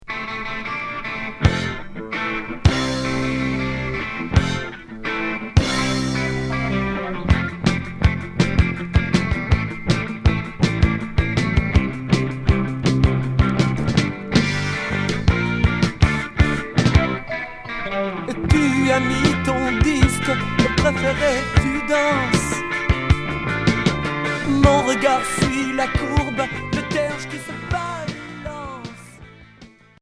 Rock et reggae